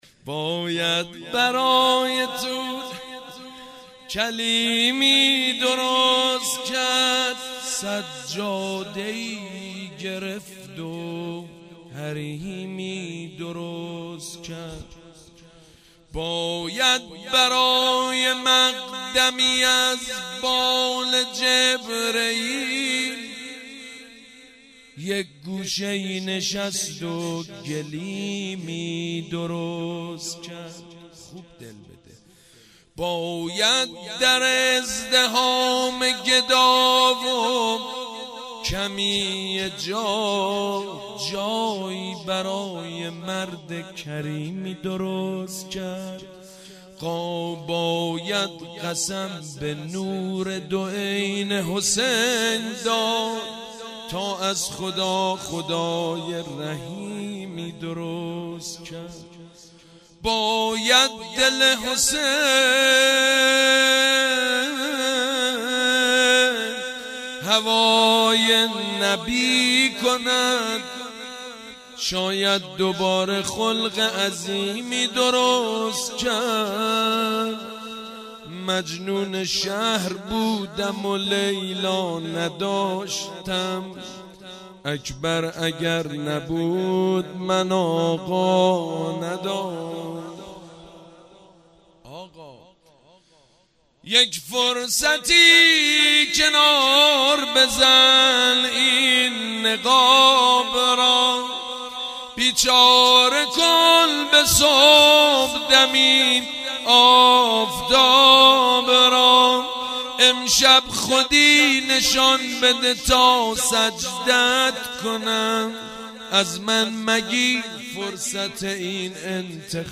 مدح: باید برای طور کلیمی درست کرد
مدح: باید برای طور کلیمی درست کرد خطیب: سید مجید بنی فاطمه مدت زمان: 00:05:59